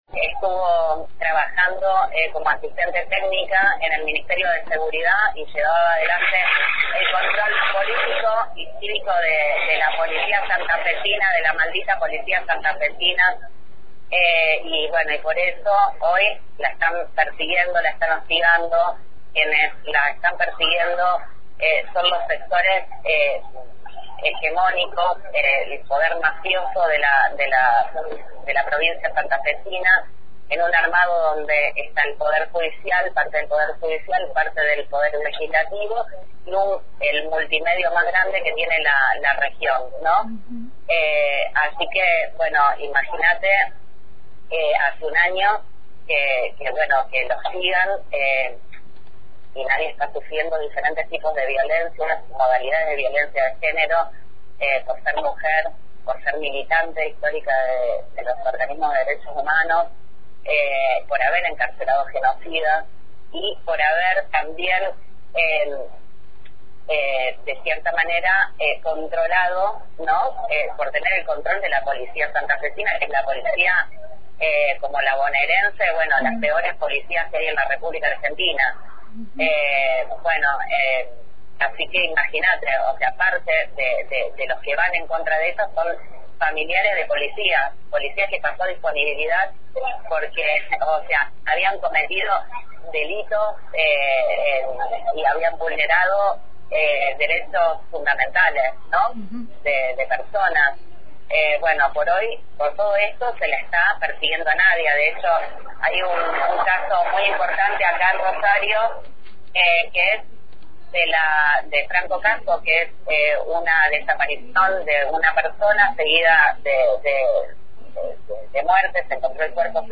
El Hilo Invisible dialogamos en vivo
entrevista